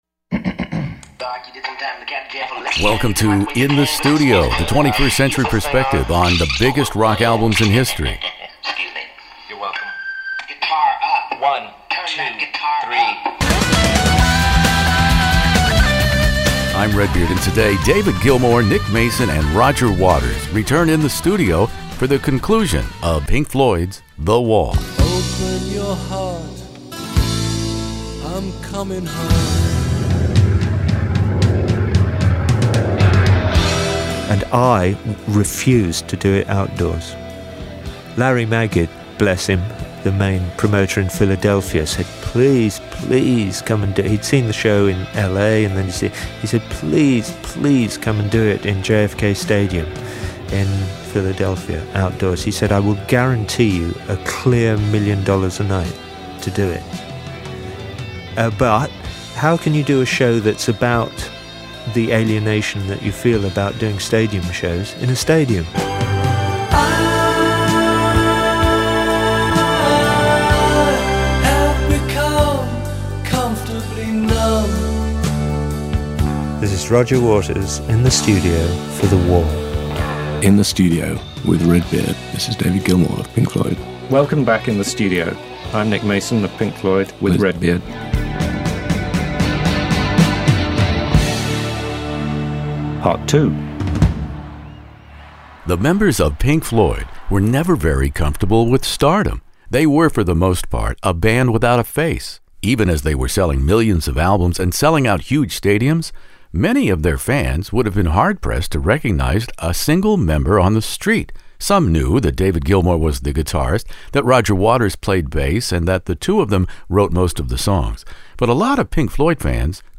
Waters talks about the original Pink Floyd album, the limited initial live performances in late 1979 and early 1980, and The Wall film which followed three years after the album, with pre-Live Aid organizer and Boomtown Rats singer Bob Geldof as the disillusioned, increasingly isolated “Pink”. David Gilmour and Nick Mason also disassemble The Wall pt2 on its forty-fifth anniversary in my classic rock interview here.